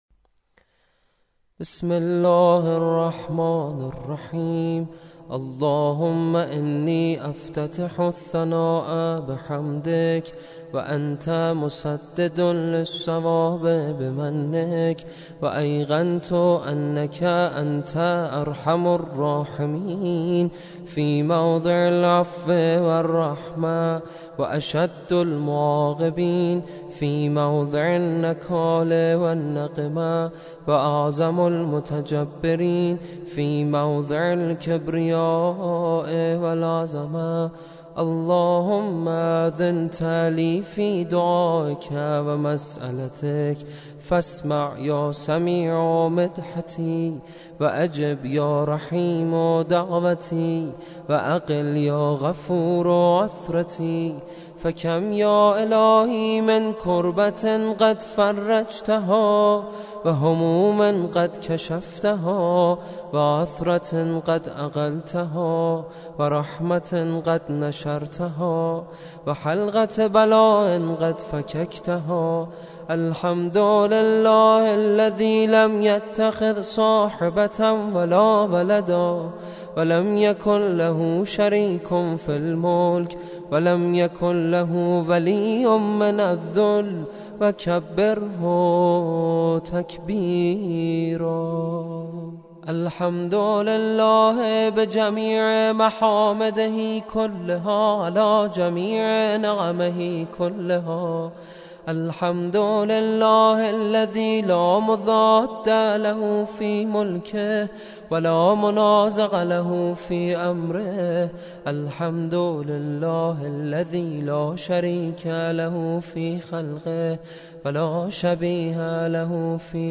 قرائت دعای افتتاح